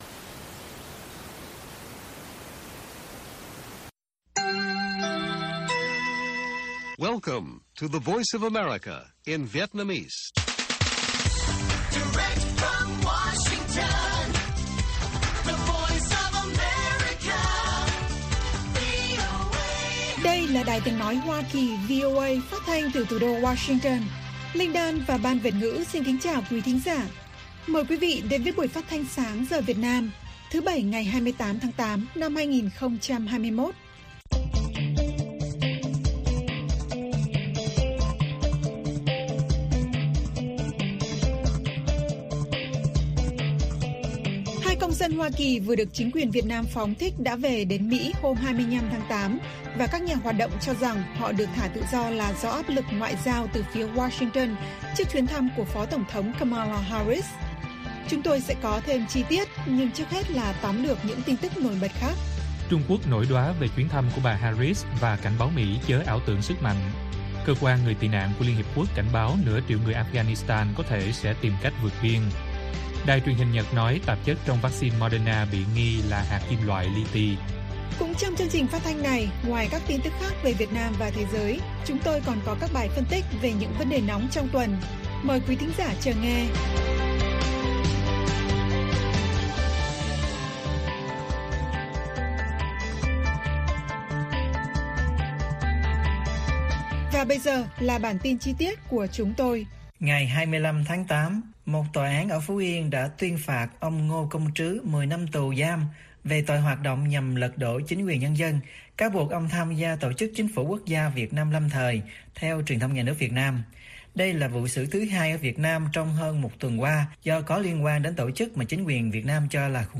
Bản tin VOA ngày 28/8/2021